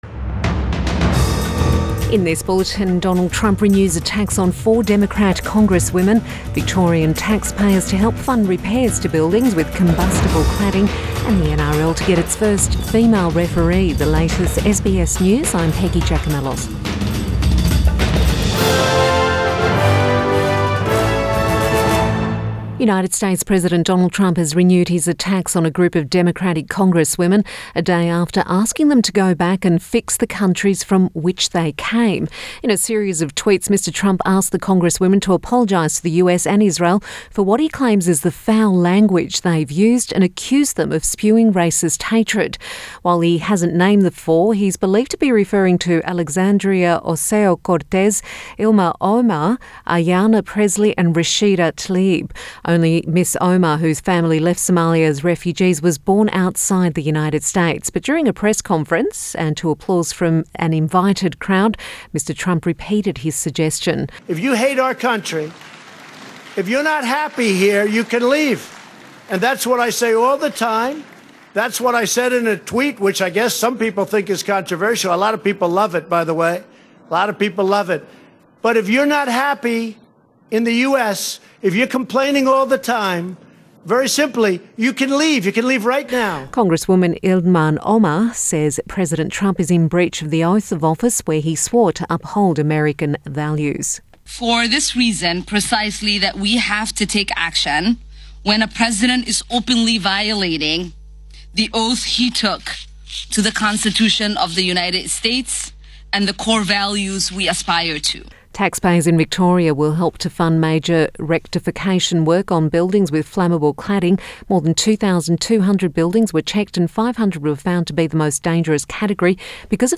Midday bulletin 16 July 2019